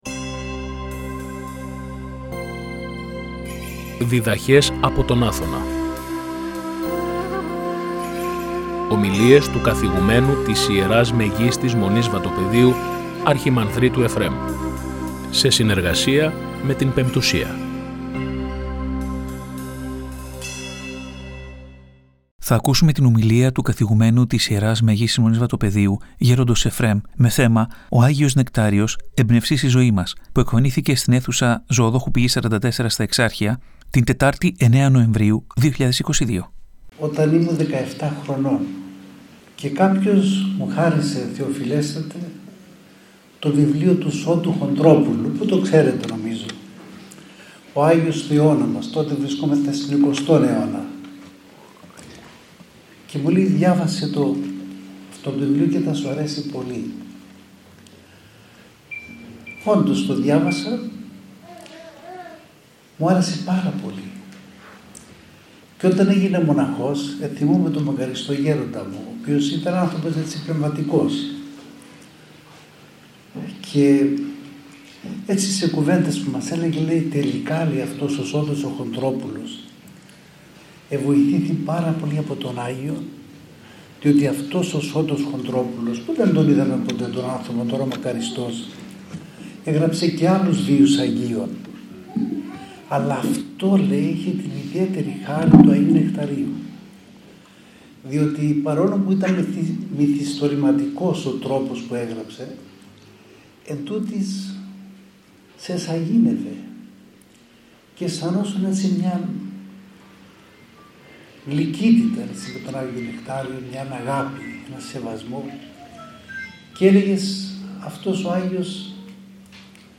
Η ομιλία μεταδόθηκε στην εκπομπή «Διδαχές από τον Άθωνα» στη συχνότητα του Ραδιοφωνικού Σταθμού της Πειραϊκής Εκκλησίας την Κυριακή 10 Νοεμβρίου 2024.